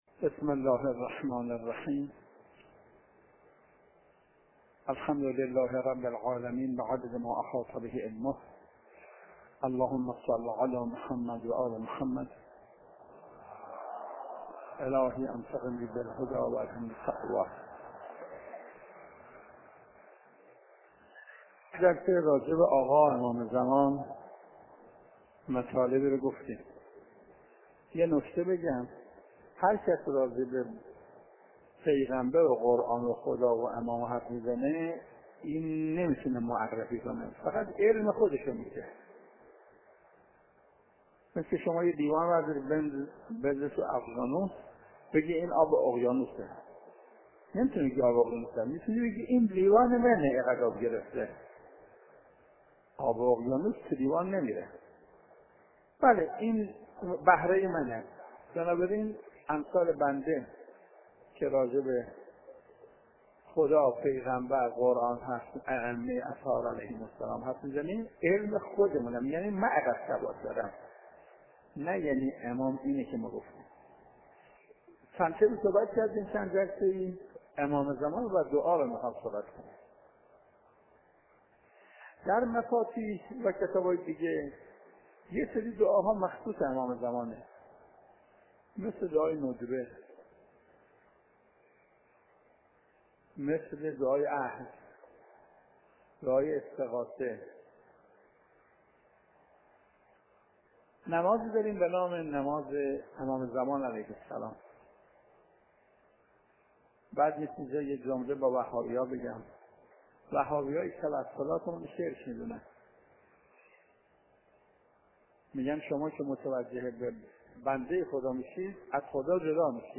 حجم: 6.52 MB | زمان: 27:10 | مکان: حرم امام رضا (علیه السلام) | تاریخ: ۲ / خرداد / ۱۳۹۷ش